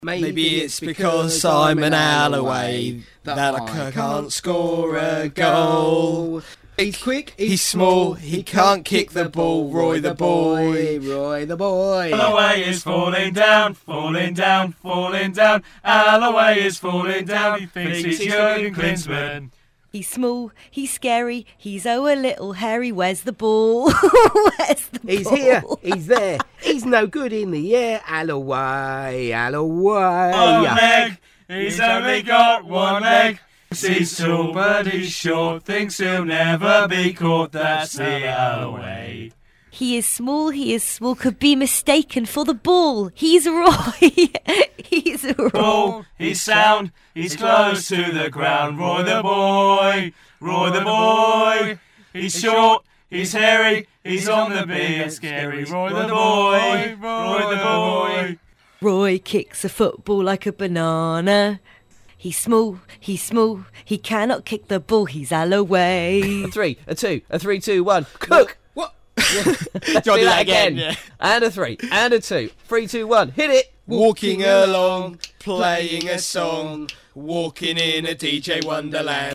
footy chants
footychantsmixdown.mp3